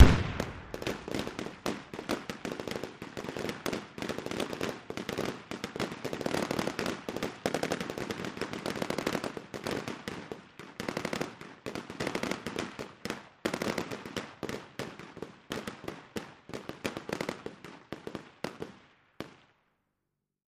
Battle simulation with explosion and rapid fire weapons. Weapons, Gunfire Bombs, War Battle, Military